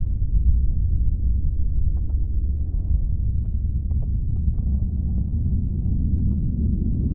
env_sounds_lava.2.ogg